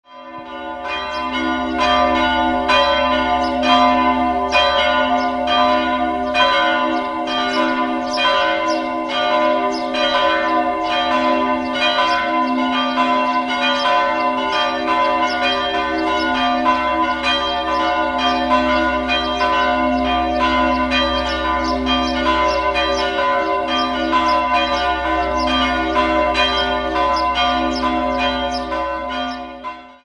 Verminderter Dreiklang: h'-d''-f'' Große Glocke h' 80 cm 1693 Wolff Hieronymus Heroldt, Nürnberg Mittlere Glocke d'' 65 cm 1668 Wolff Hieronymus Heroldt, Nürnberg Kleine Glocke f'' 55 cm 1815 Joseph Stapf, Eichstätt